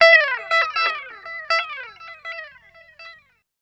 70 GTR 1  -R.wav